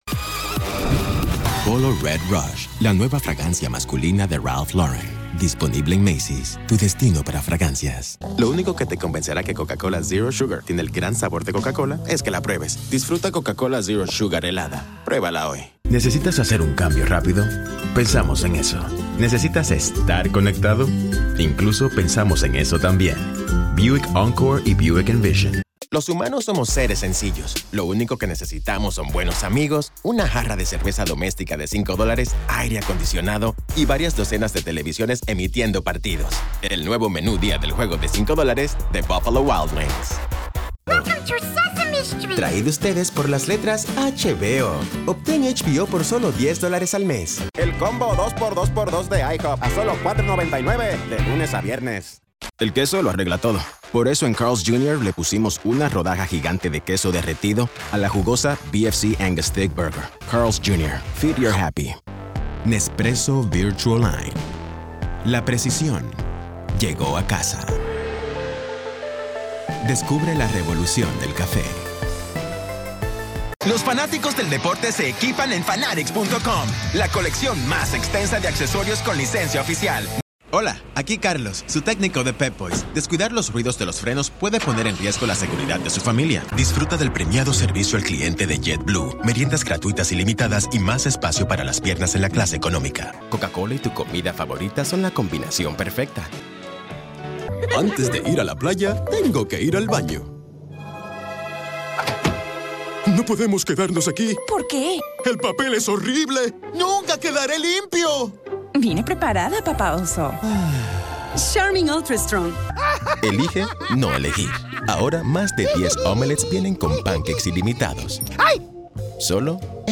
Commercial Spanish